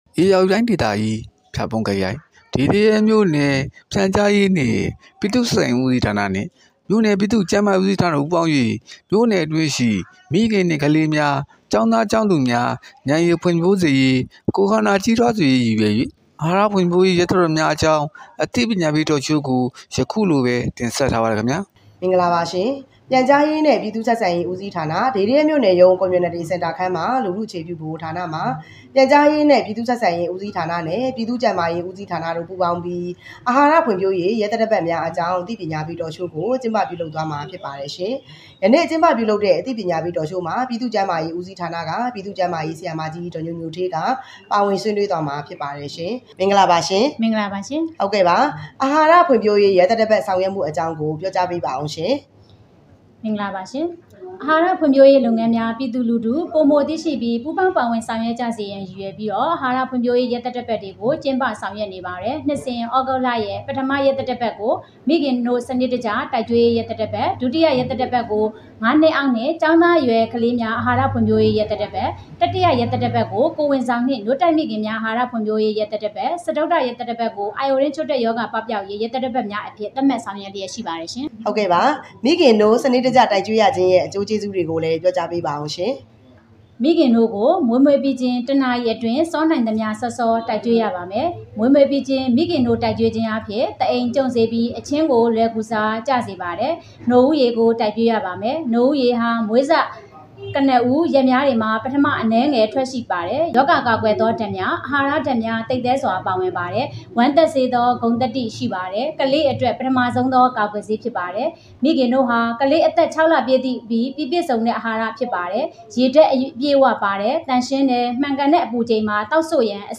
Community Centre ခန်းမတွင်အာဟာရဖွံ့ဖြိုးရေးရက်သတ္တပတ်များအကြောင်း အသိပညာပေး Talk Show ကျင်းပ